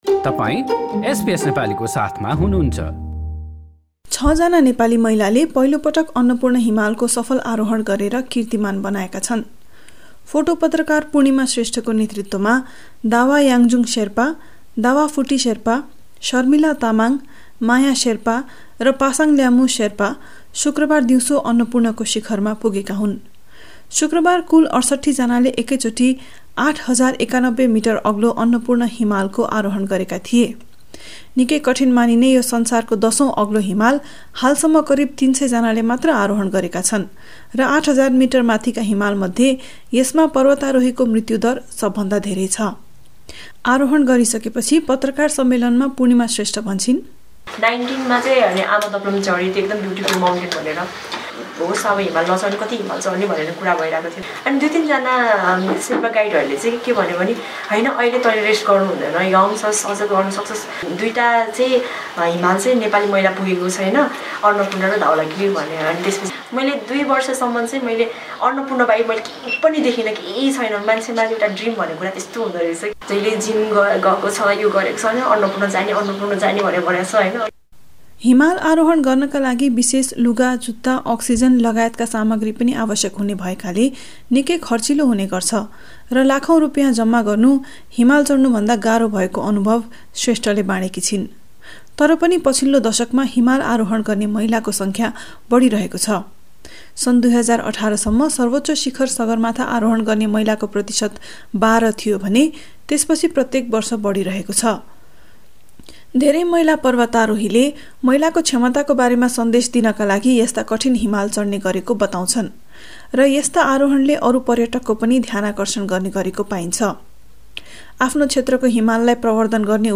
काठमान्डूबाट
रिपोर्ट।